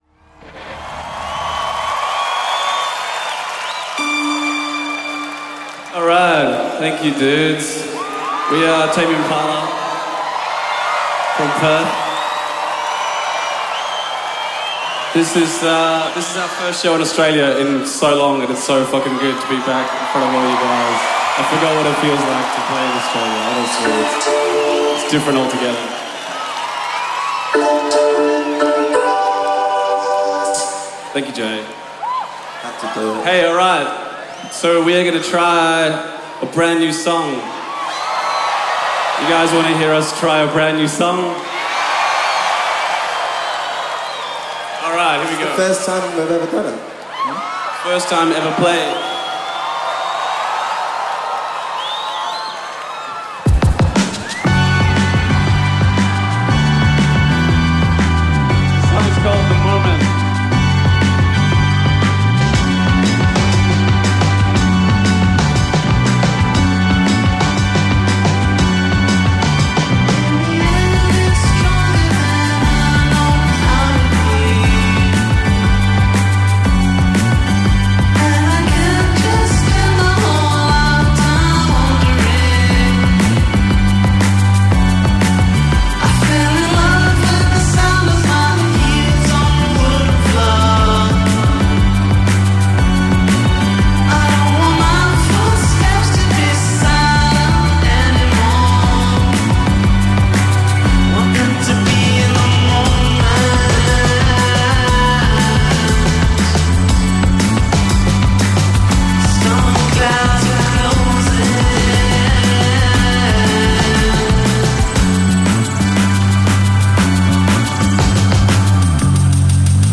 New-Psychedelia